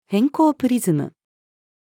偏光プリズム-female.mp3